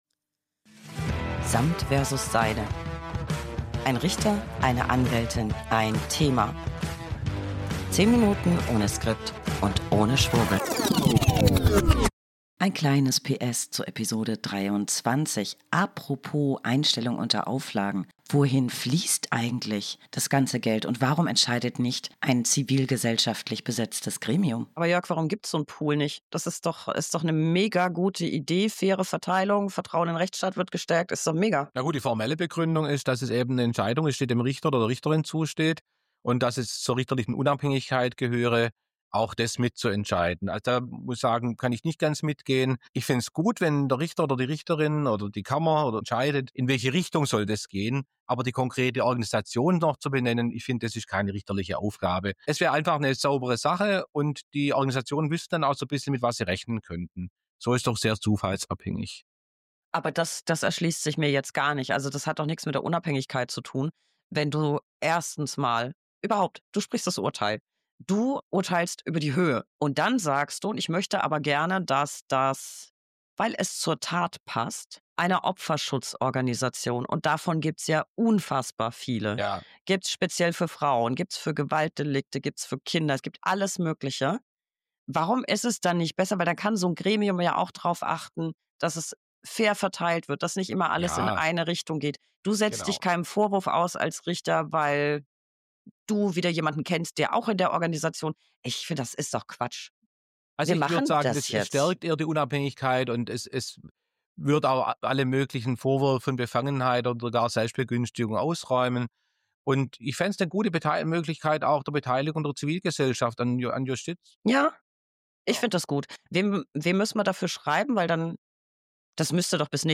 1 Anwältin + 1 Richter + 1 Thema.